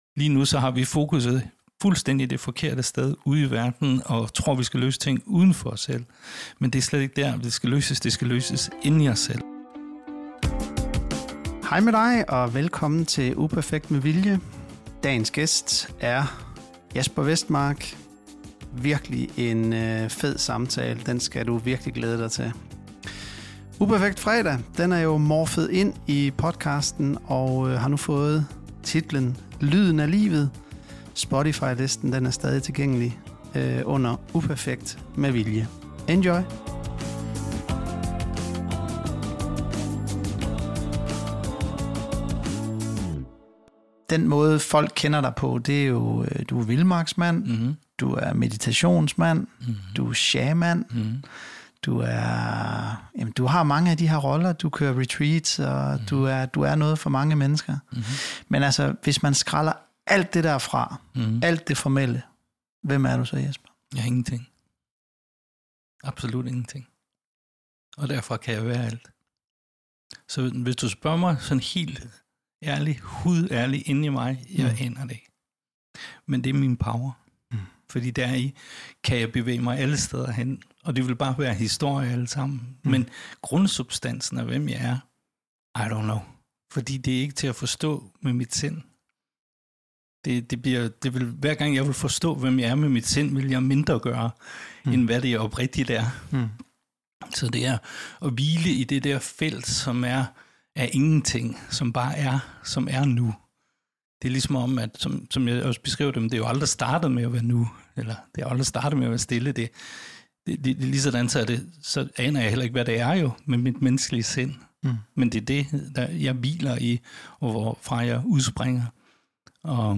En virkelig dejlig dag i studiet.